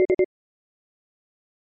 Ambient Computer Sounds » Computer Beeps
描述：Some futuristic computer beeps I generated and edited in audacity.
标签： futuristic Future beep beeps bleep computer
声道立体声